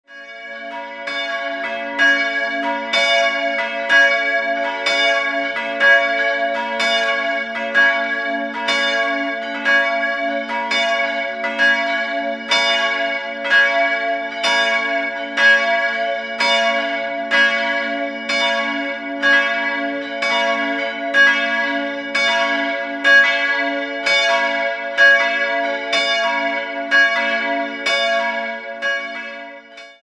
Innenansicht wird noch ergänzt. 3-stimmiges Geläute: b'-des''-ges'' Nähere Daten liegen nicht vor. Ein nettes Kleingeläute im seltenen Dur-Sextakkord.